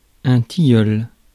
Ääntäminen
Synonyymit tilleul à grandes feuilles Ääntäminen France: IPA: /ti.jœl/ Haettu sana löytyi näillä lähdekielillä: ranska Käännös Substantiivit 1. липа {f} (lipá) Suku: m .